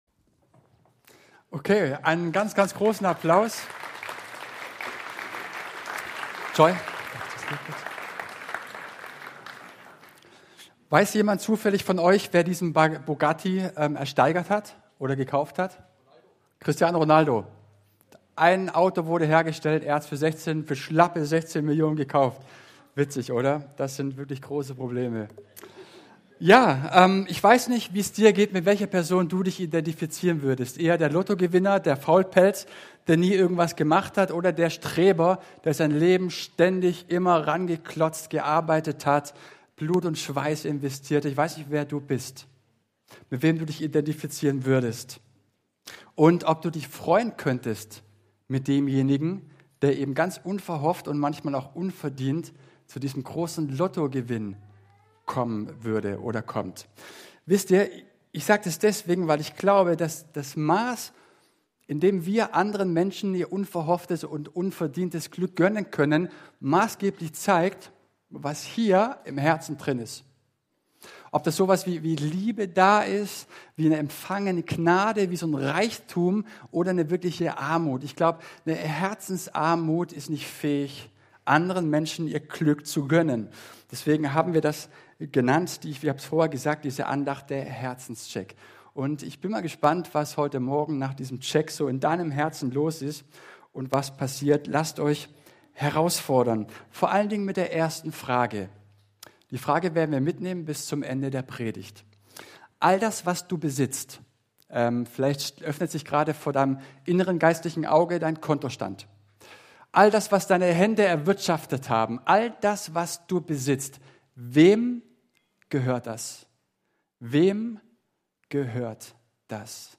Gottesdienst zur Jugendsegnung